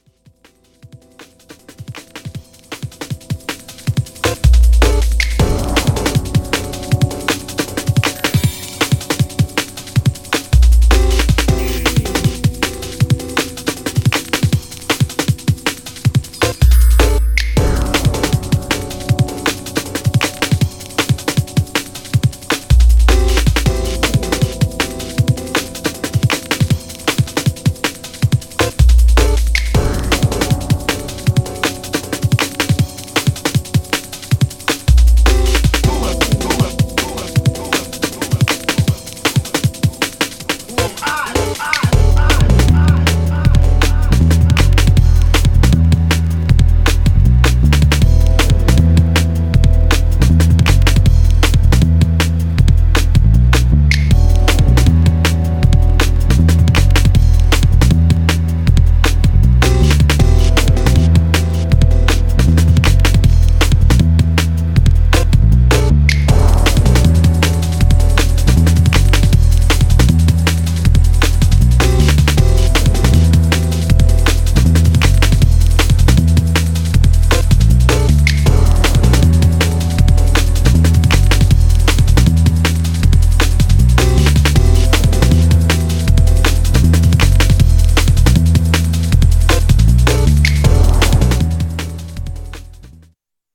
Styl: Drum'n'bass, Jungle/Ragga Jungle, Lounge